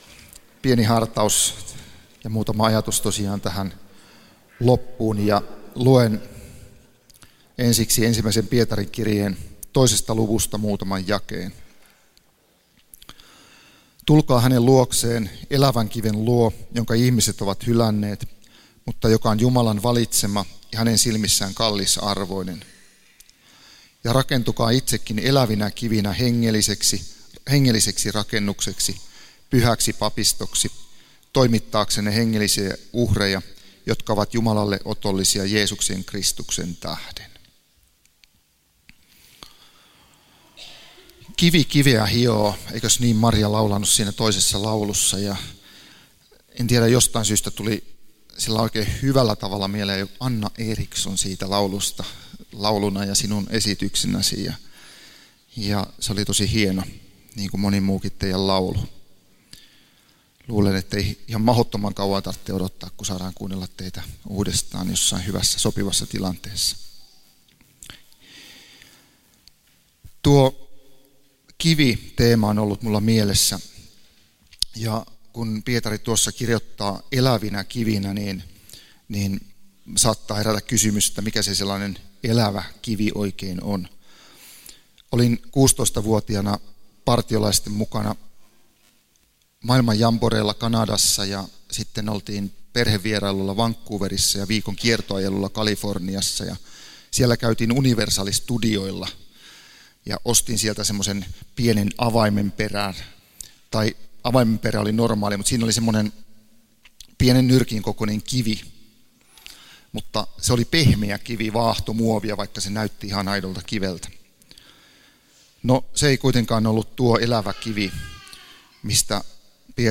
Kokoelmat: Tampereen evankeliumijuhlat 2019